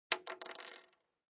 Descarga de Sonidos mp3 Gratis: caida alfiler.